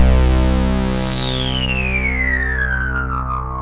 KORG C2 1.wav